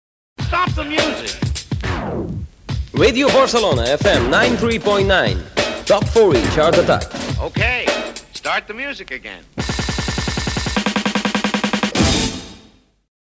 Identificació de l'emissora i del programa en anglès.
FM